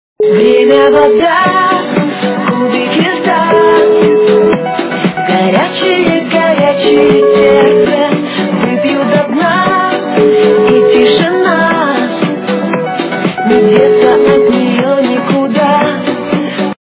- русская эстрада
При заказе вы получаете реалтон без искажений.